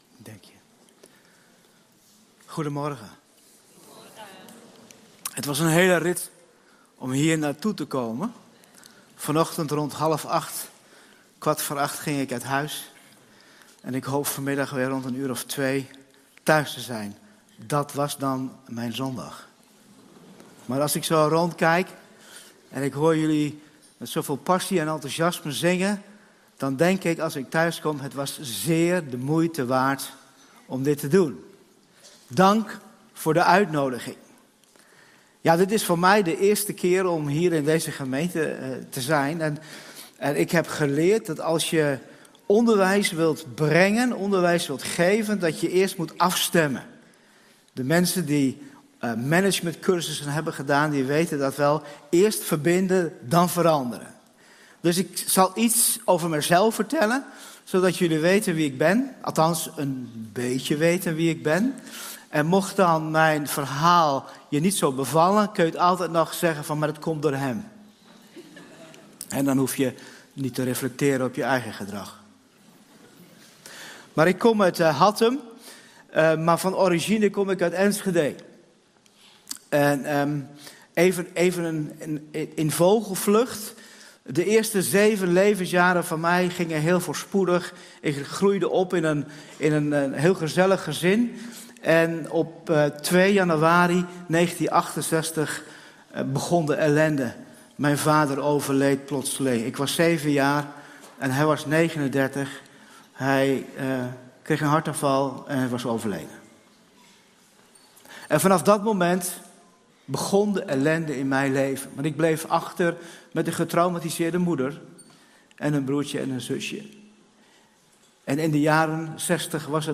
versie van de preek